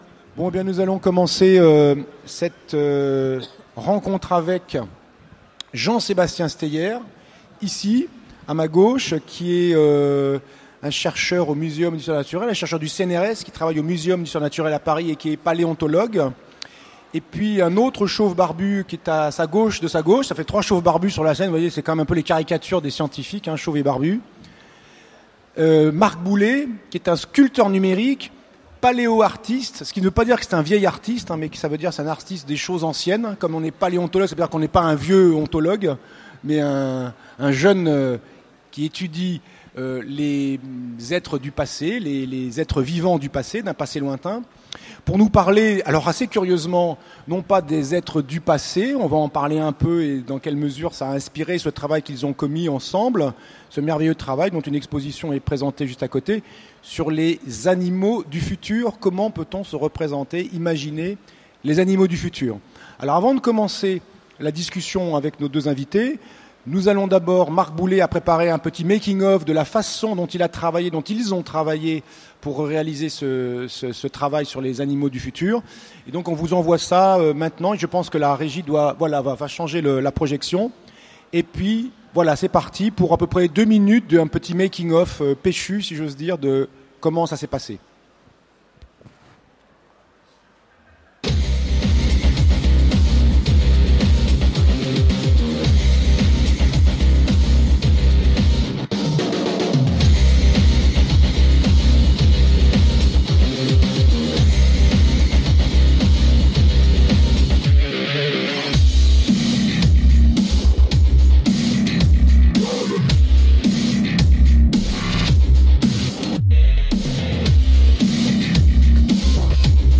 Utopiales 2015 : Conférence Les animaux du futur - ActuSF - Site sur l'actualité de l'imaginaire